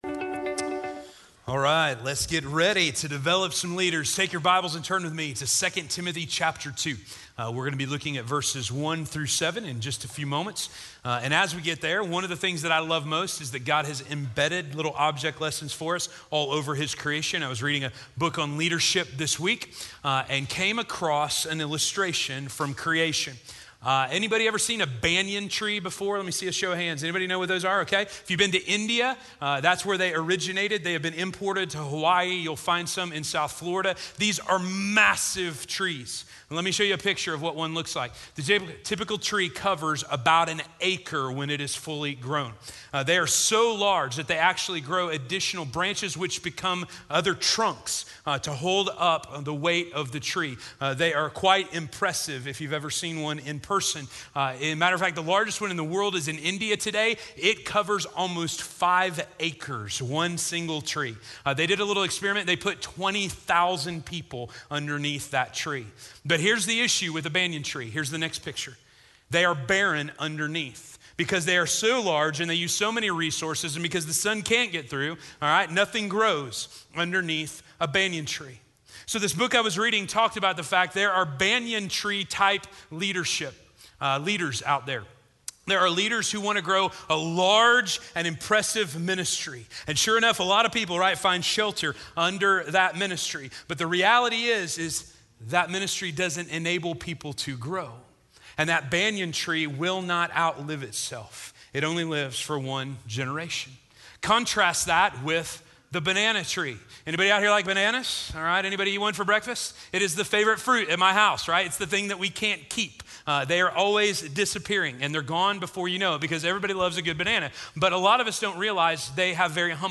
Growing the Leaders We Need - Sermon - Station Hill